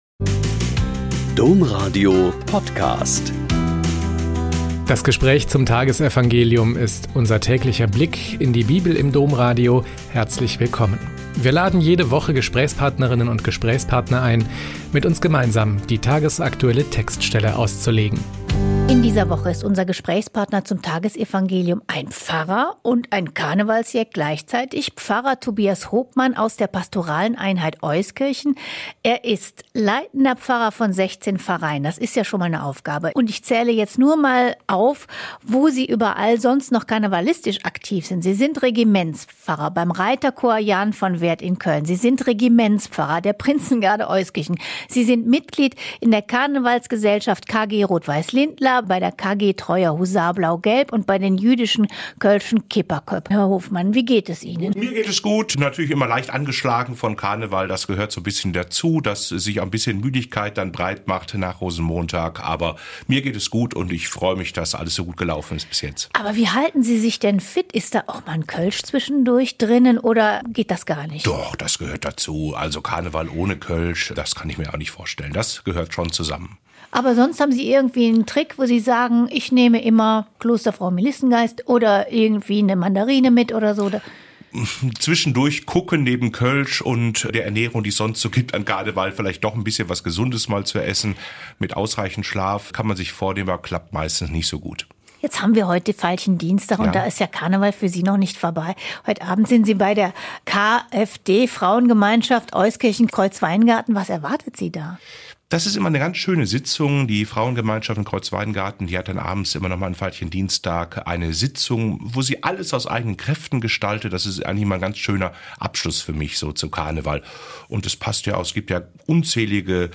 Mk 10,28-31 - Gespräch